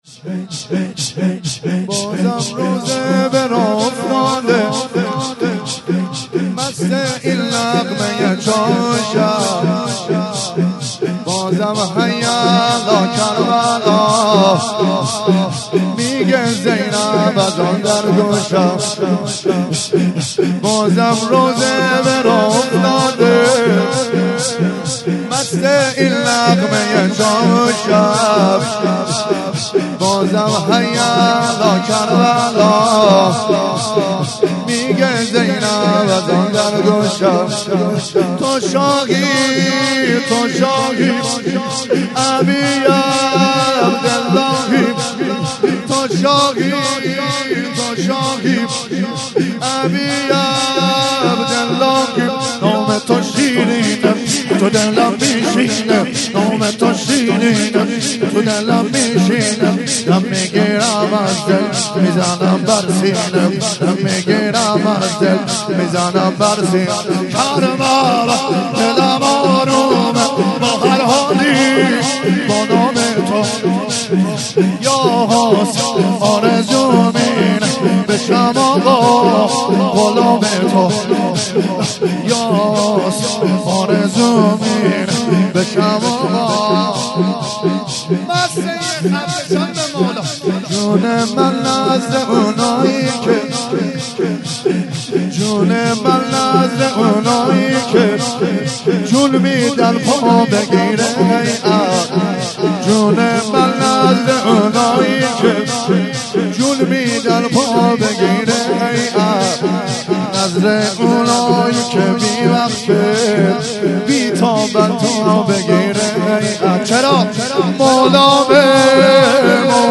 5- جون من نذز اونایی که - شور